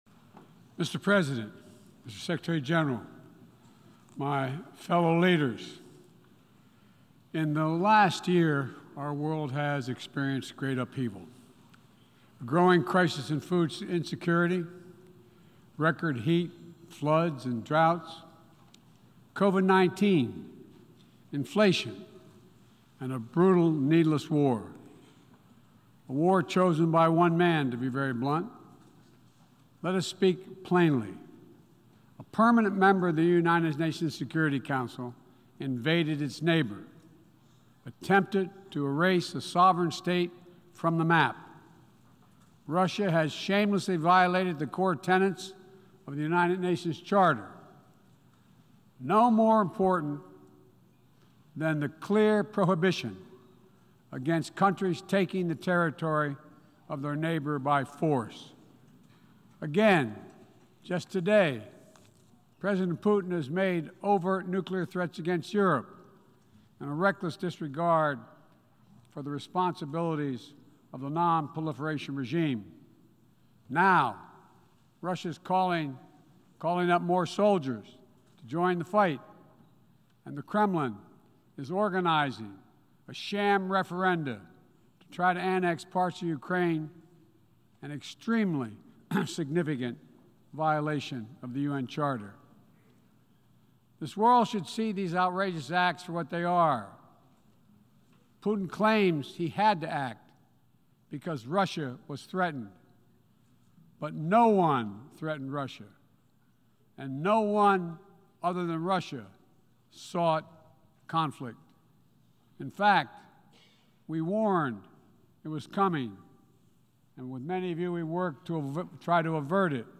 September 21, 2022: Speech before the 77th Session of the United Nations General Assembly | Miller Center
Presidential Speeches | Joe Biden Presidency